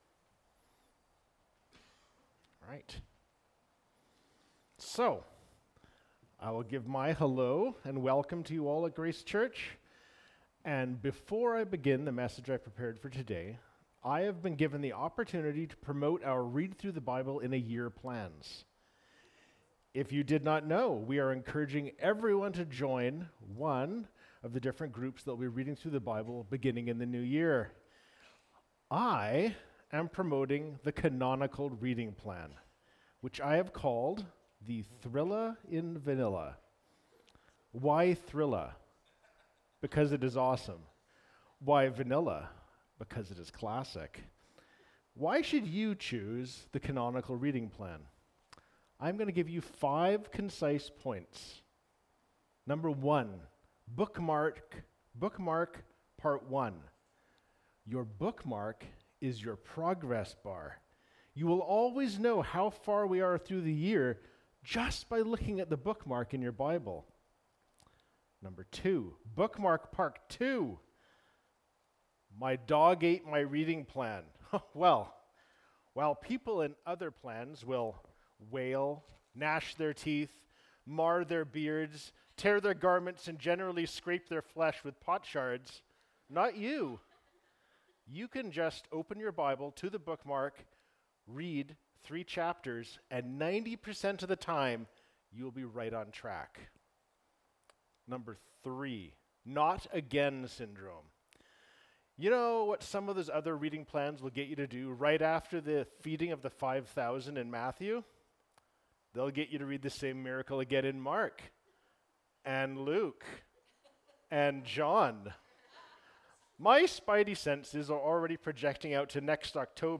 Dec 03, 2023 The Second Servant Song (Isaiah 49:1-13) MP3 SUBSCRIBE on iTunes(Podcast) Notes Discussion Sermons in this Series This sermon was recorded in Grace Church Salmon Arm (but also preached in Grace Church Enderby).